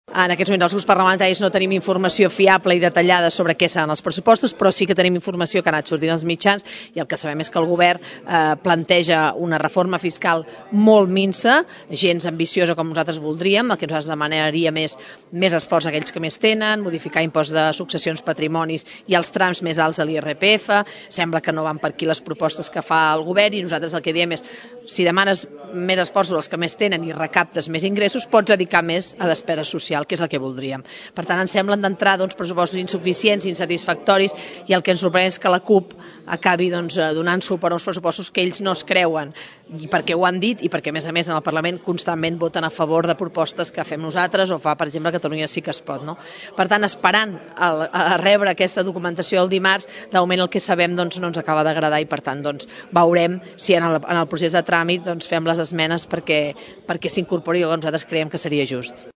En declaracions a Ràdio Tordera, Romero defineix com a delicada la situació política al nostre municipi i es planteja diverses qüestions.